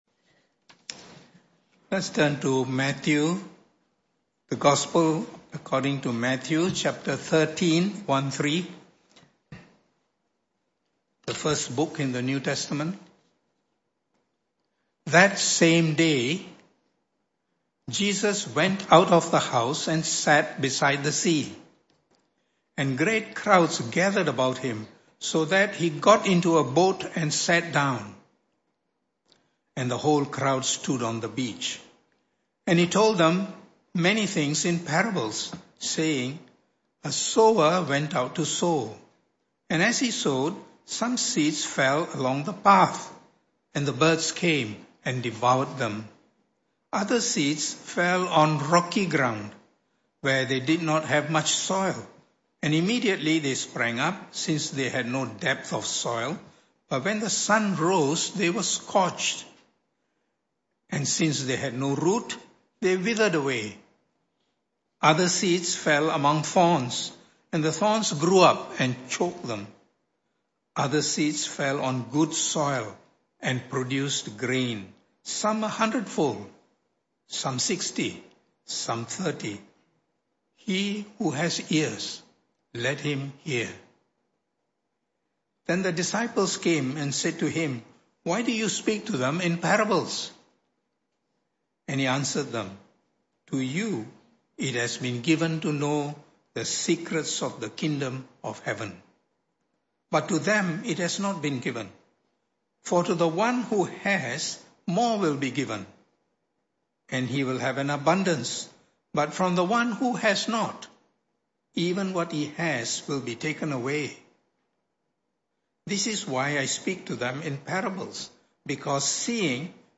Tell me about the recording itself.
This talk was part of the AM Service series called The Message Of Matthew.